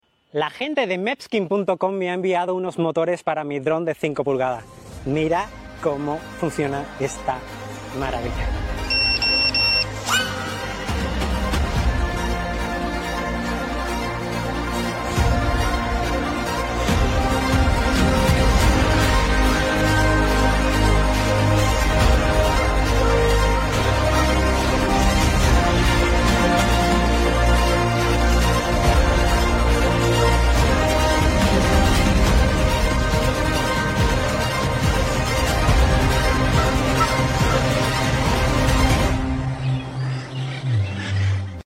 Fun takeoff, pure FPV vibes sound effects free download